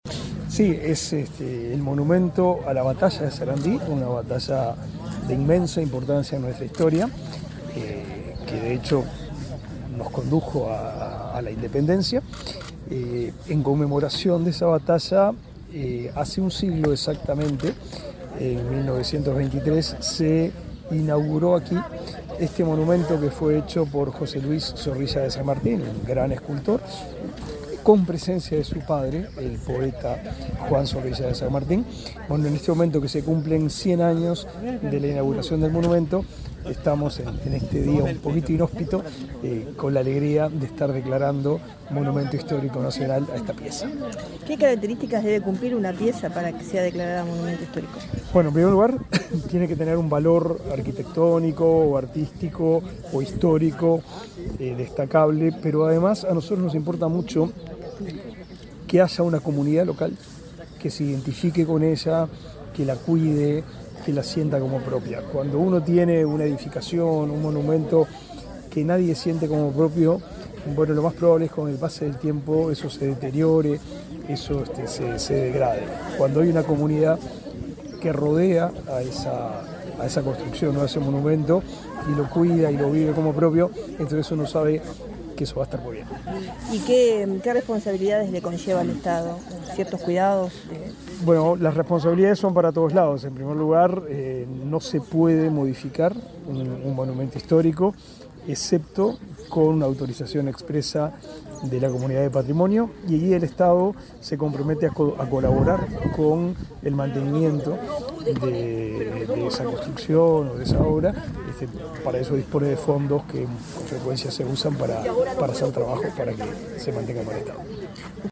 Declaraciones del ministro de Educación y Cultura, Pablo da Silveira
Declaraciones del ministro de Educación y Cultura, Pablo da Silveira 31/10/2023 Compartir Facebook X Copiar enlace WhatsApp LinkedIn El presidente de la Comisión del Patrimonio Cultural de la Nación, William Rey, y el ministro Pablo da Silveira participaron, este martes 31 en Florida, del acto que declara monumento histórico a la estatua por la Batalla de Sarandí. Antes, el secretario de Estado dialogó con Comunicación Presidencial.